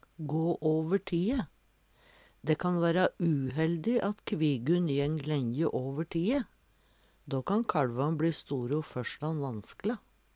gå åver tie - Numedalsmål (en-US)